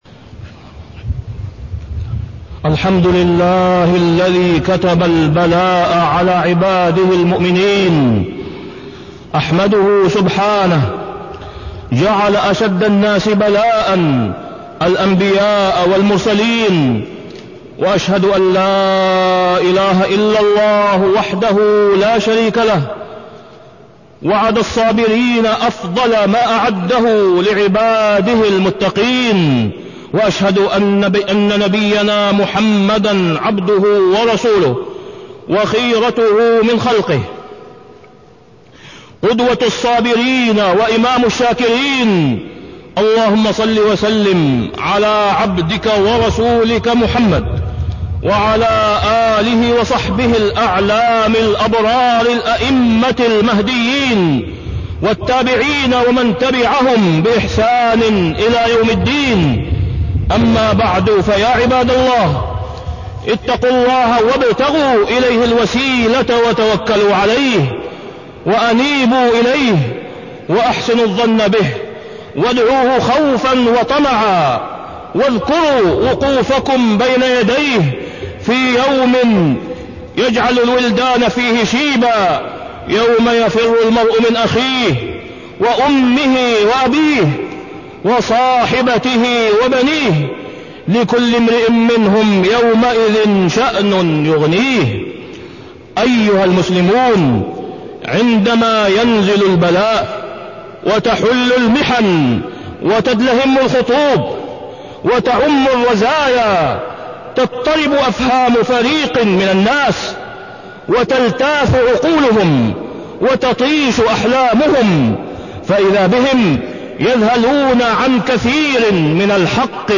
تاريخ النشر ٢ صفر ١٤٢٤ هـ المكان: المسجد الحرام الشيخ: فضيلة الشيخ د. أسامة بن عبدالله خياط فضيلة الشيخ د. أسامة بن عبدالله خياط الإبتلاء